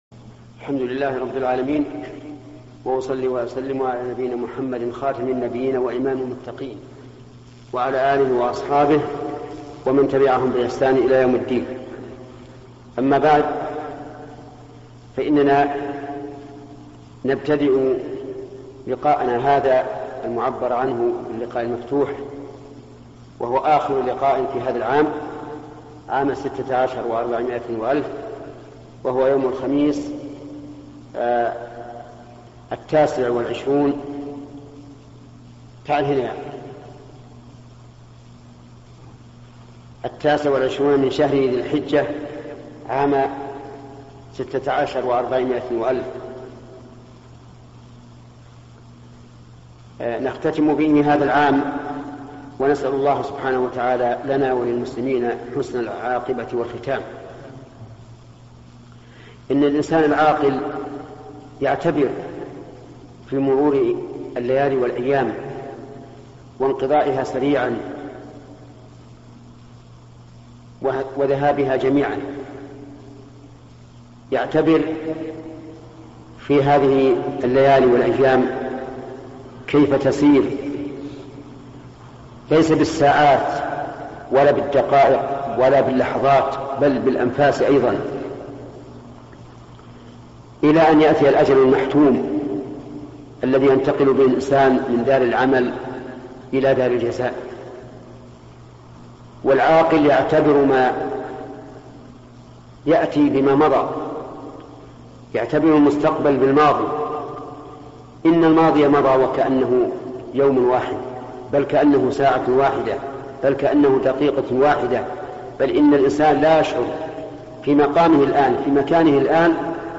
موعظة بمناسبة نهاية العام الهجري - الشيخ ابن عثيمين - مشروع كبار العلماء